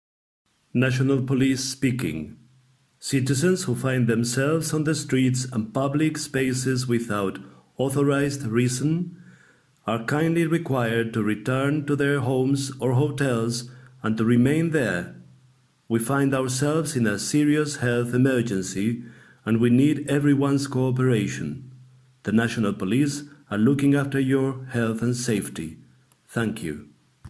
La Policía Nacional está emitiendo, desde el día de hoy, un comunicado en formato de audio que pretende concienciar a las personas que se encuentran en la calle de la importancia que tiene permanecer en casa: "Se requiere a los ciudadanos que, sin causa justificada, se encuentra en las vías y espacios públicos, se dirijan de manera inmediata a sus domicilios".
Este es el audio de la policía en inglés